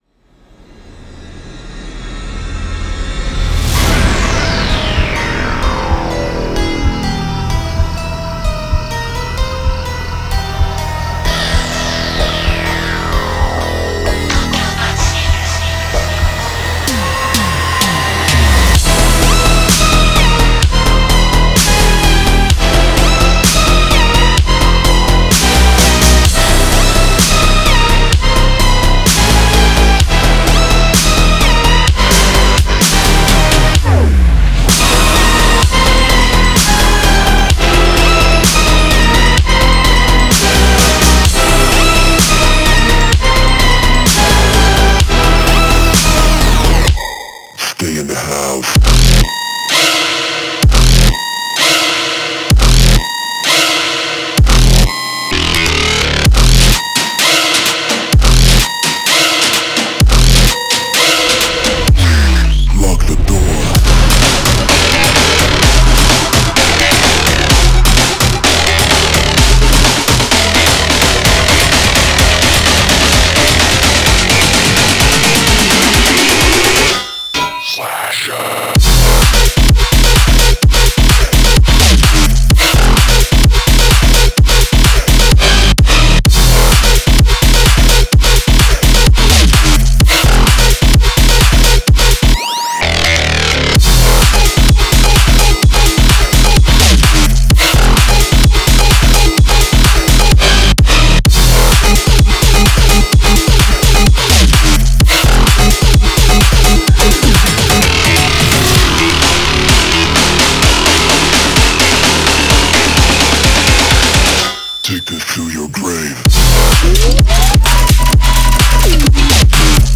EDM, Dark, Gloomy, Epic